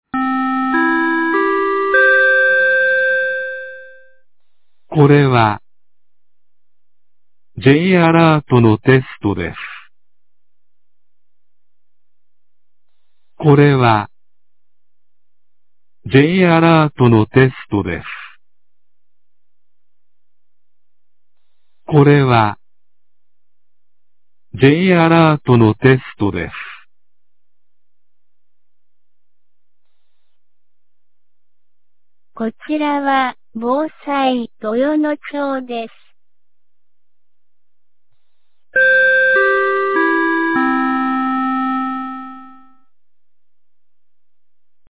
2025年11月12日 11時00分に、豊能町に放送がありました。
放送音声